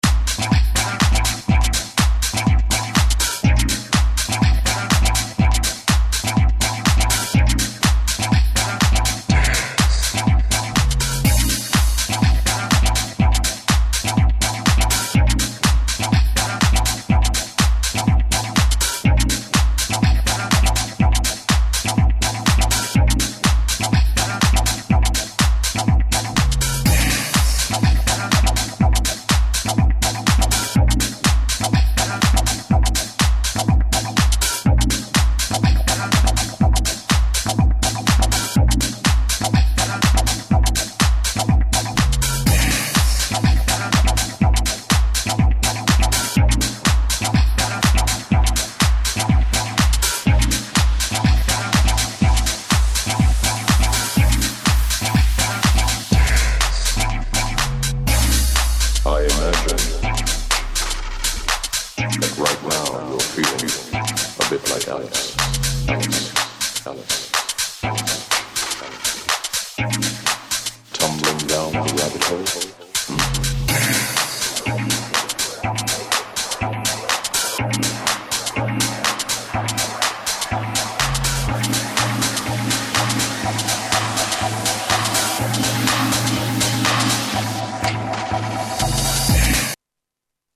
Electro House Acid